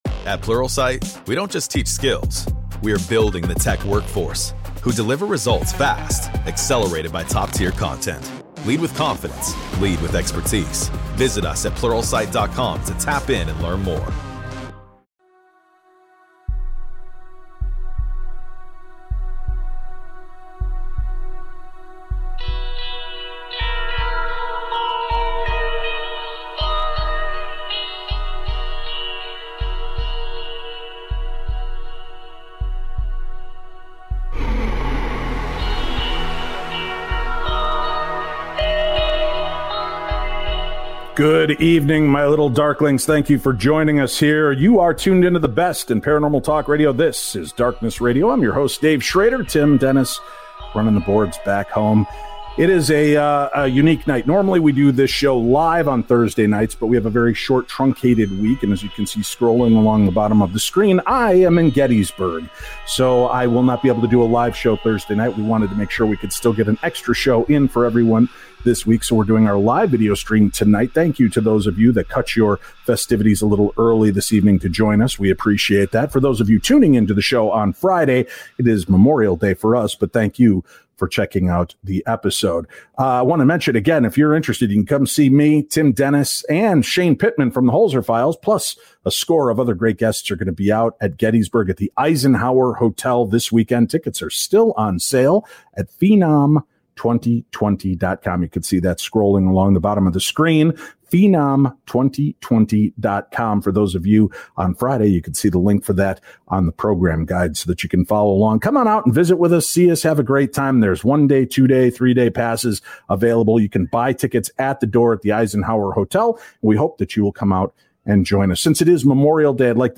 paranormal investigators and experiencers